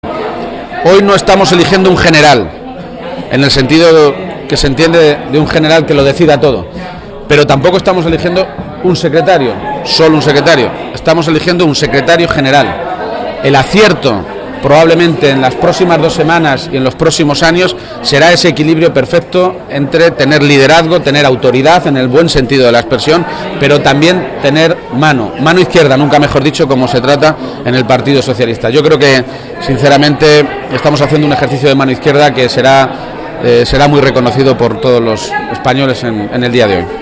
García-Page realizó estas declaraciones después de votar en la sede del PSOE de Toledo donde estuvo acompañado por su madre